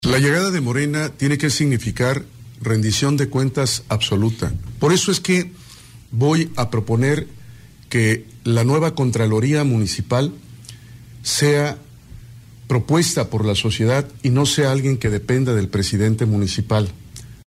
Habla Rubén Gregorio Muñoz Álvarez